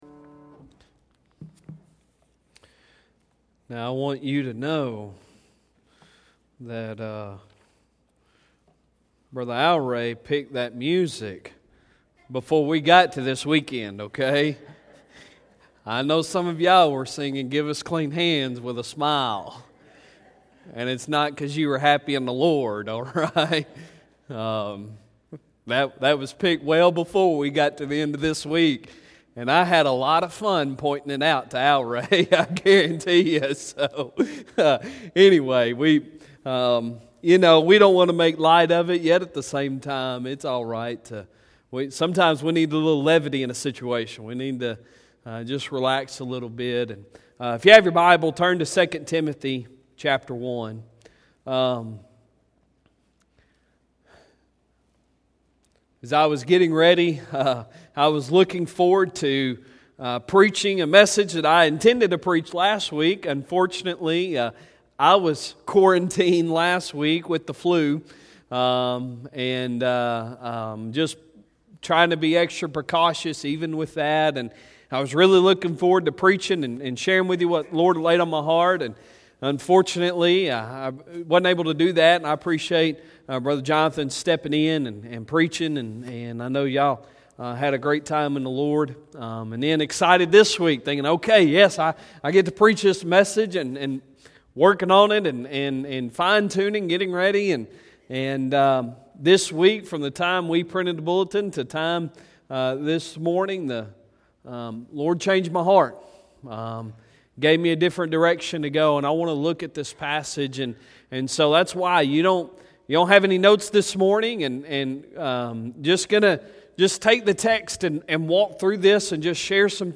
Sunday Sermon March 15, 2020